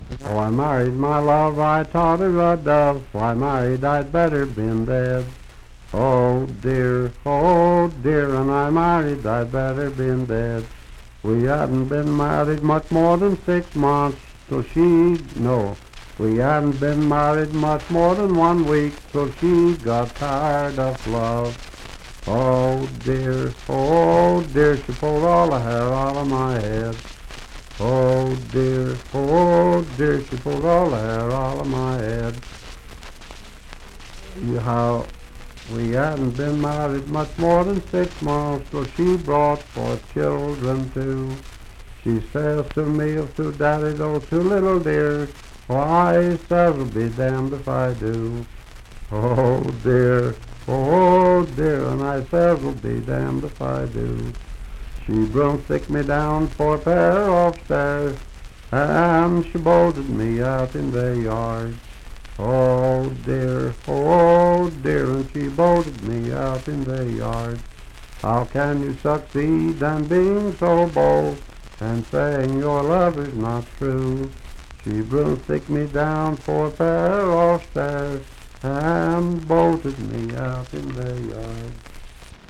Unaccompanied vocal music
Verse-refrain 5(4-6w/R).
Voice (sung)